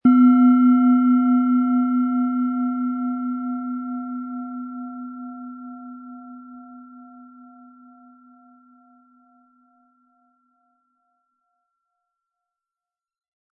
Sie sehen und hören eine von Hand gefertigt Delfin Klangschale.
Spielen Sie die Delfin mit dem beigelegten Klöppel sanft an, sie wird es Ihnen mit wohltuenden Klängen danken.
SchalenformBihar
MaterialBronze